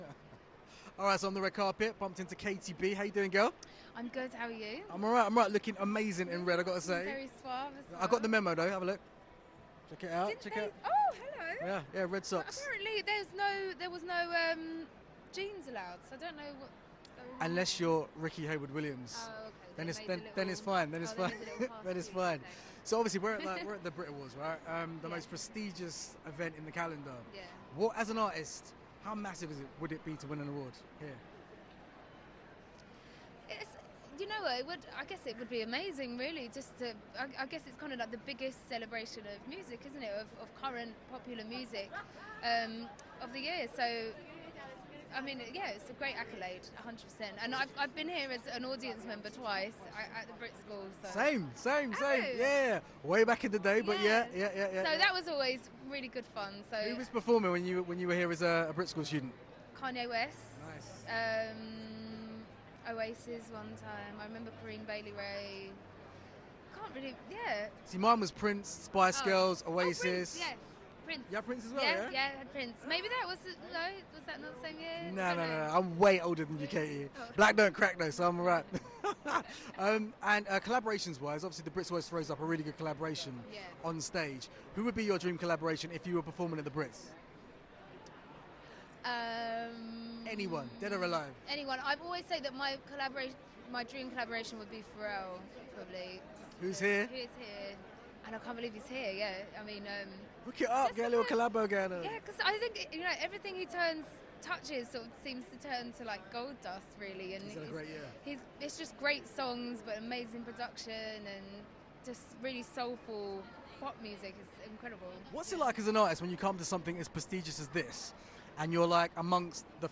Katy B chats to us on the red carpet at the Brits!